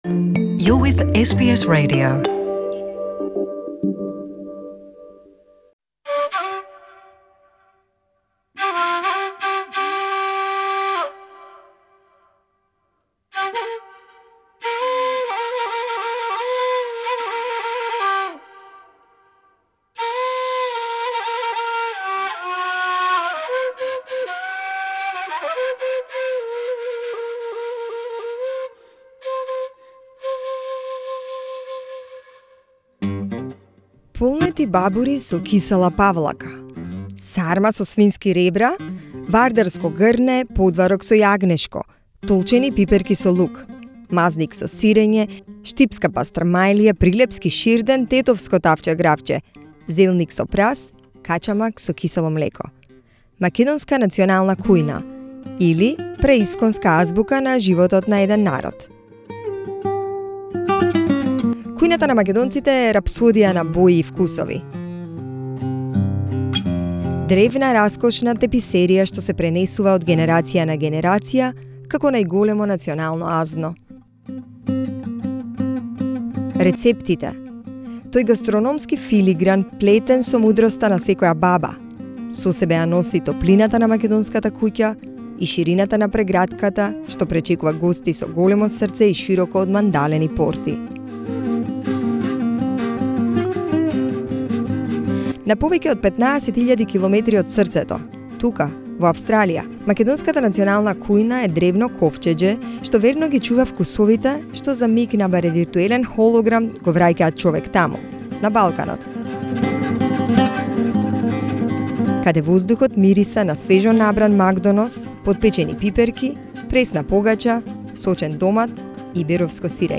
“ This inspired us at SBS Macedonian to do some research on the chances of Macedonian food to compete with other cuisines in multicultural Australia, where nearly every ethnic cuisine in the world can be found, but yet only few have a real dominance on the market. In order to get some answers we discussed this topic with several restaurateurs and cafeteria owners, which offer Macedonian food on their menu.